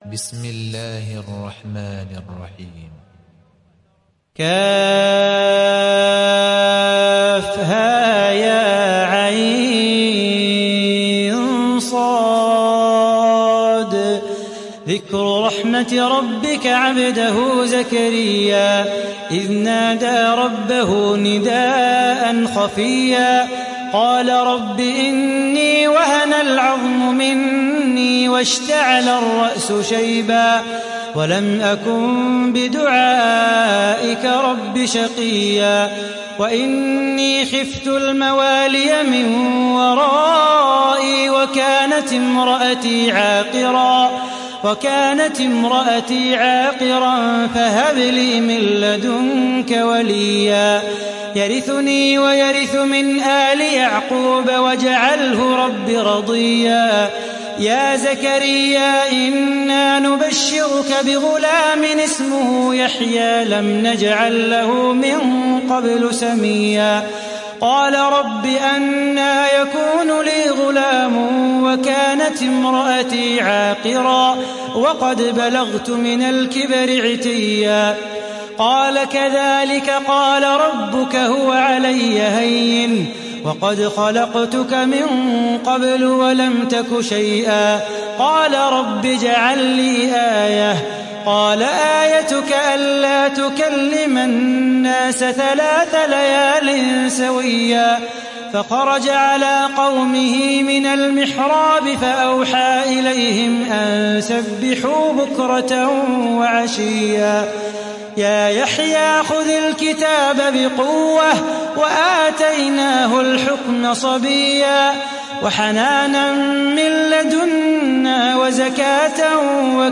دانلود سوره مريم mp3 صلاح بو خاطر روایت حفص از عاصم, قرآن را دانلود کنید و گوش کن mp3 ، لینک مستقیم کامل